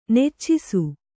♪ neccisu